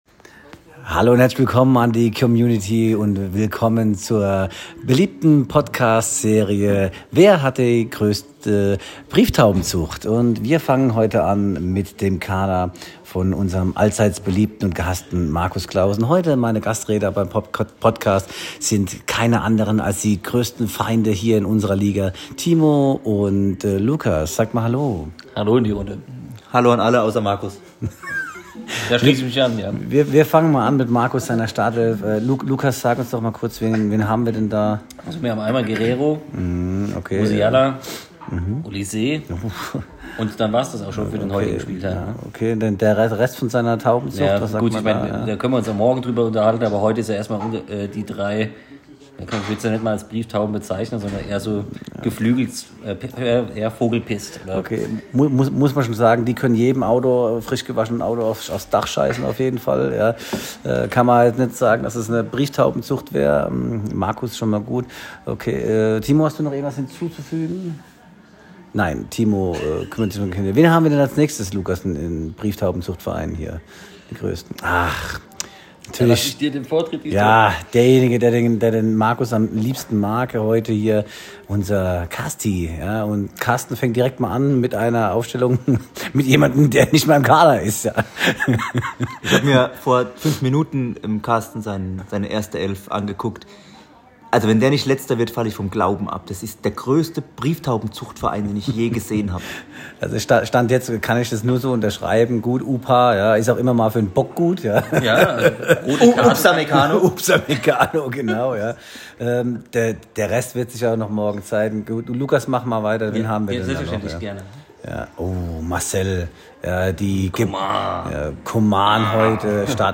Mein erster Podcast zum Thema Kickbase, unserer Liga und unserer Teams. Heute als Gastredner